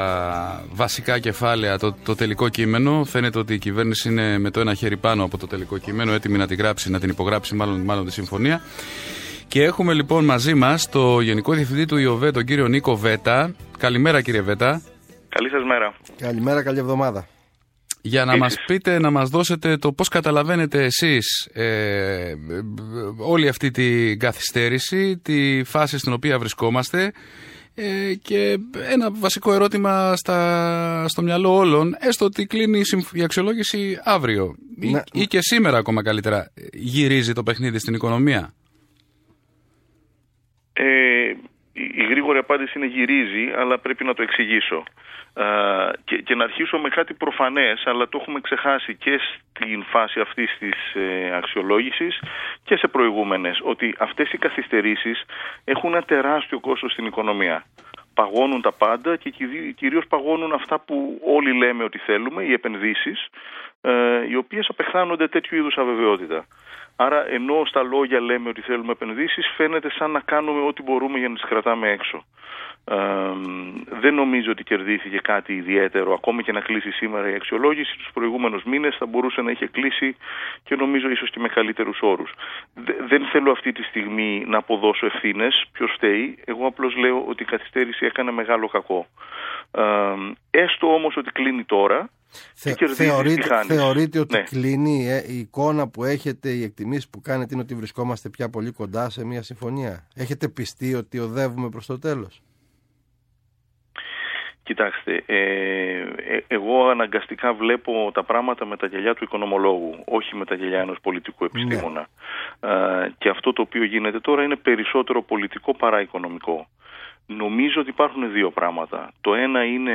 Συνέντευξη στο ΒΗΜΑ FM 99,5